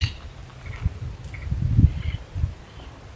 Turkey Vulture (Cathartes aura)
Location or protected area: Parque Nacional El Palmar
Condition: Wild
Certainty: Observed, Recorded vocal
Jote-cabeza-colorada_1.mp3